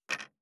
587魚切る,肉切りナイフ,まな板の上,
効果音厨房/台所/レストラン/kitchen食器食材